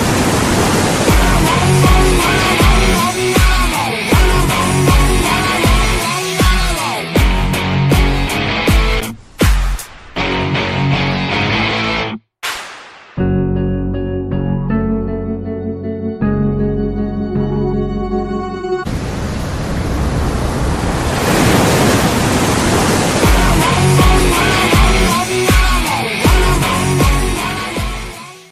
Sad Ringtones